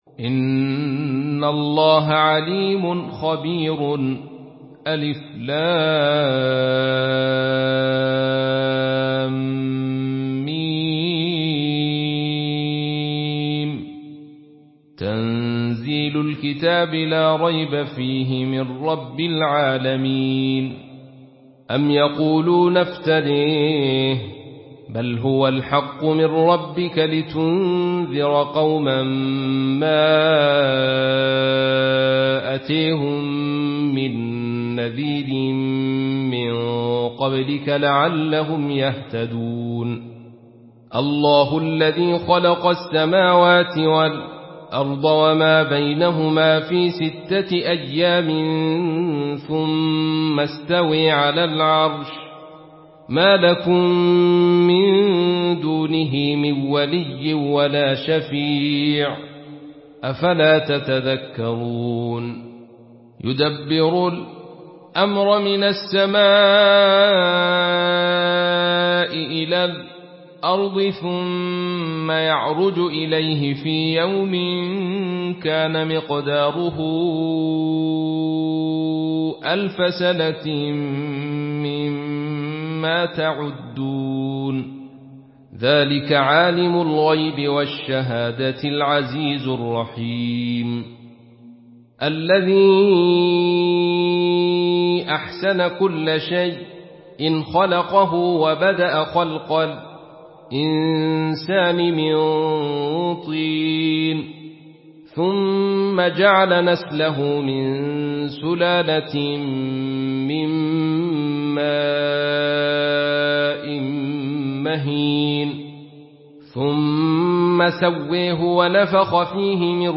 Surah As-Sajdah MP3 by Abdul Rashid Sufi in Khalaf An Hamza narration.
Murattal